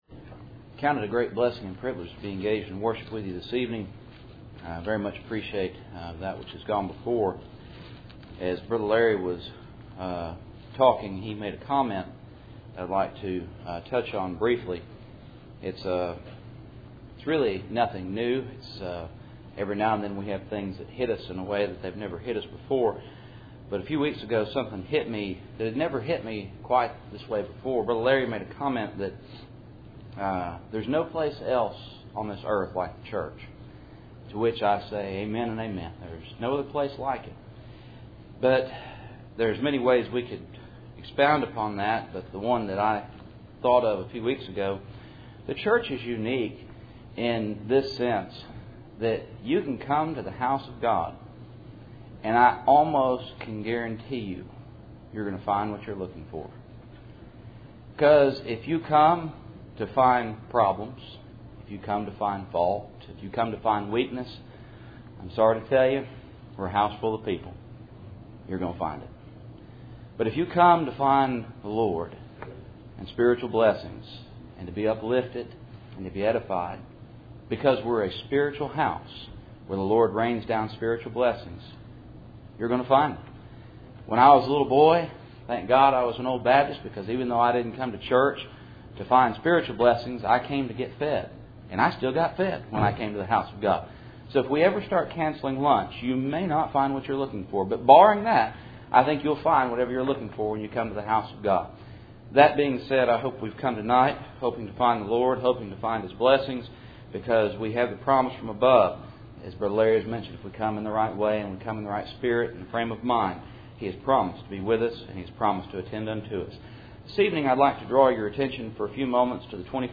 Luke 24:14-26 Service Type: Cool Springs PBC Sunday Evening %todo_render% « The Patience of David The Joy